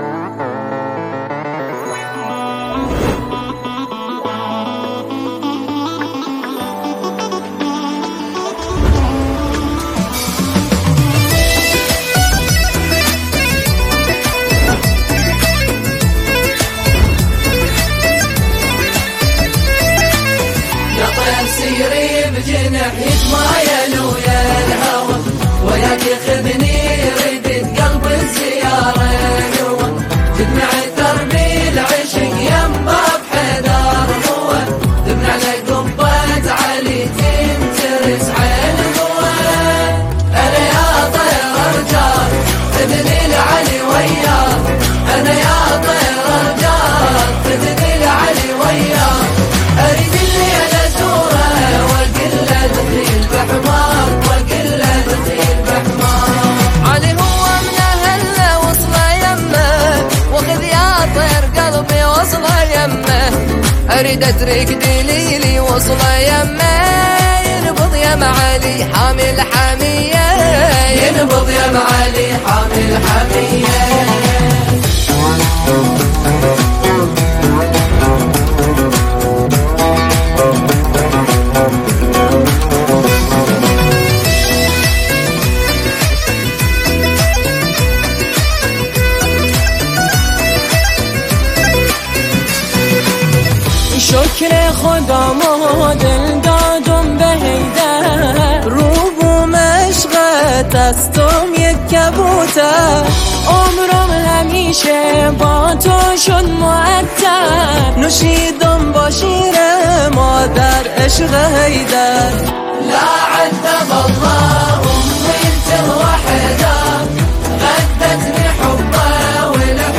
نماهنگ جدید زیبا و دلنشین